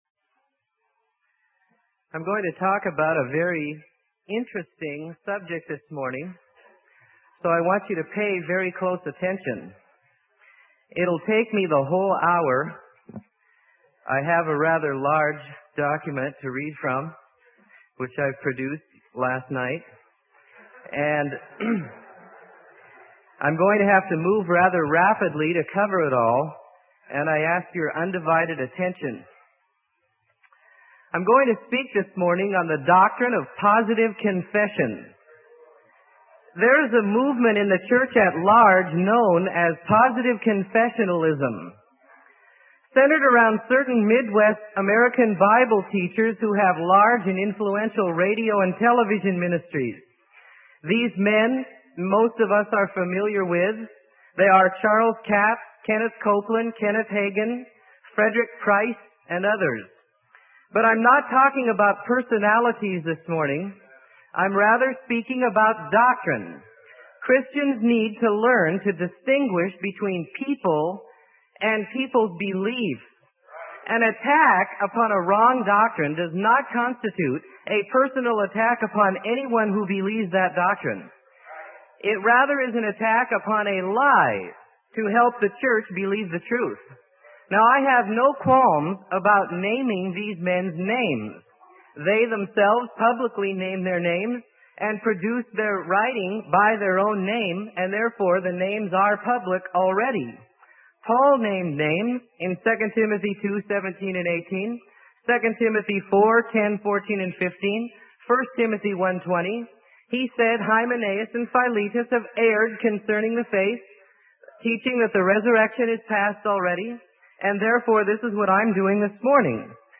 Sermon: Doctrine of Positive Confession - Freely Given Online Library